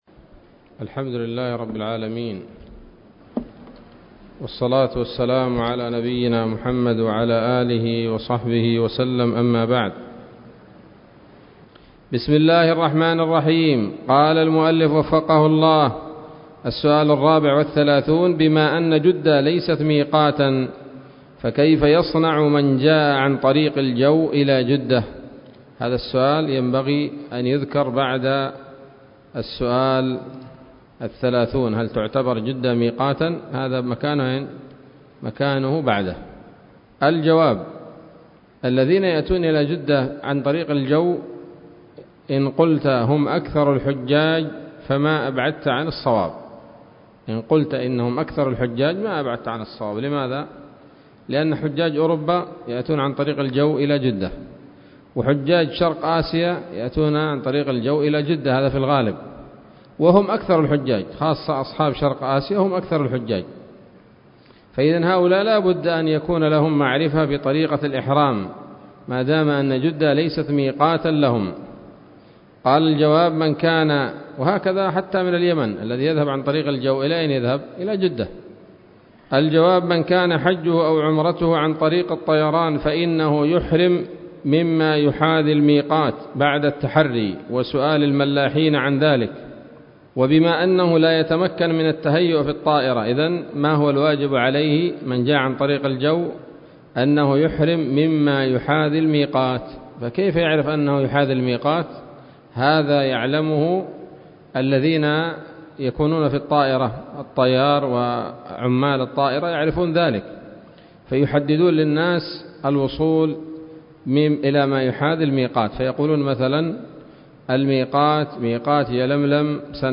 الدرس الخامس والعشرون من شرح القول الأنيق في حج بيت الله العتيق